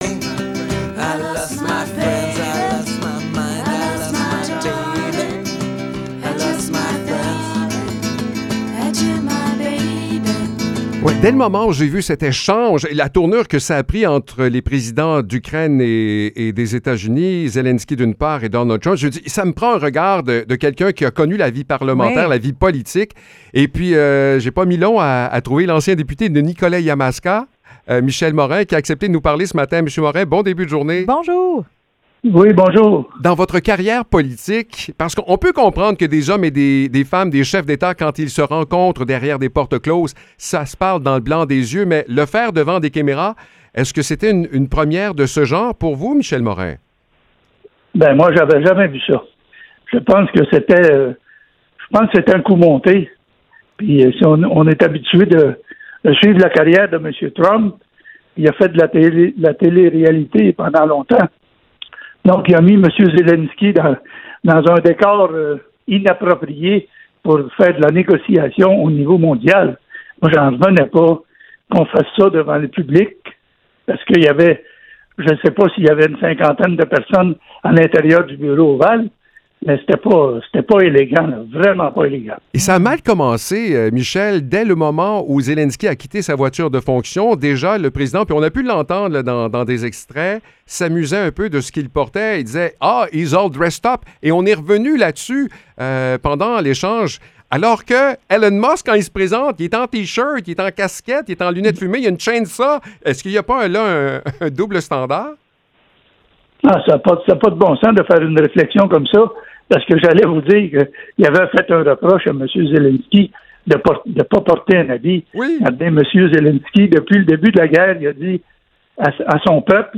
Entrevue avec l’ancien député Michel Morin